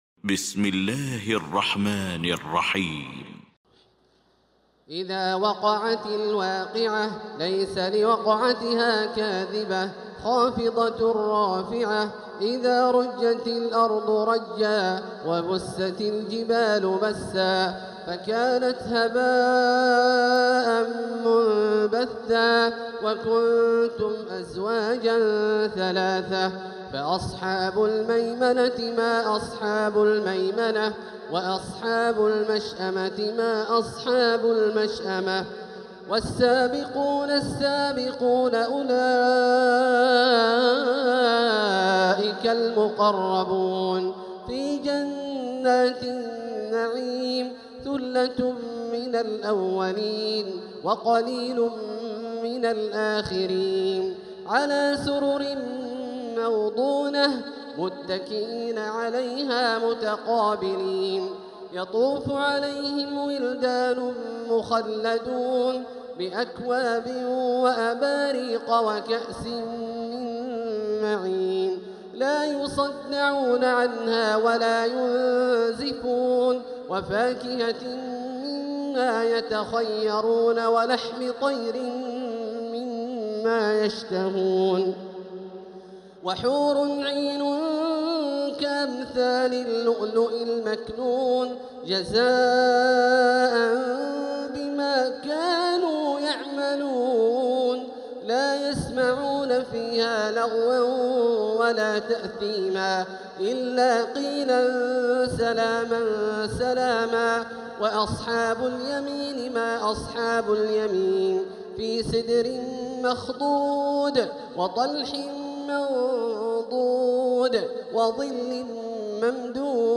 المكان: المسجد الحرام الشيخ: فضيلة الشيخ عبدالله الجهني فضيلة الشيخ عبدالله الجهني الواقعة